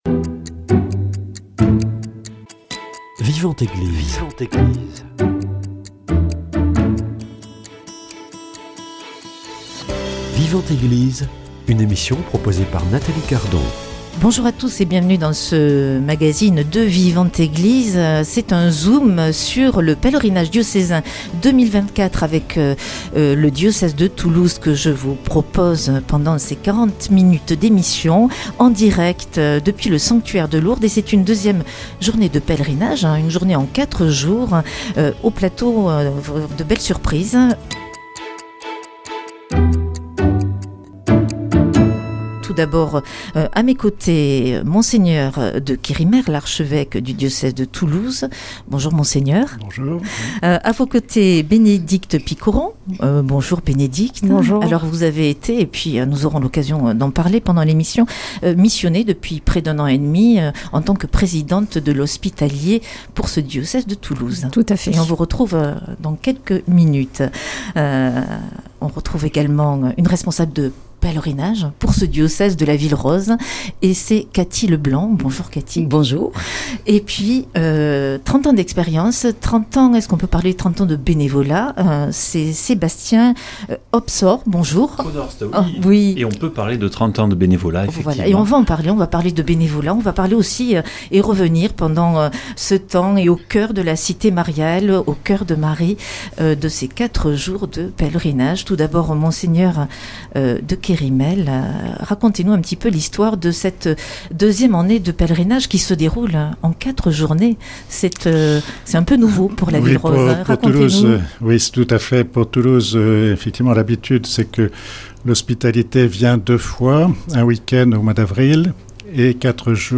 Prochain pèlerinage avec le diocèse de Toulouse du 21 au 24 août 2025 à Lourdes Invités en plateau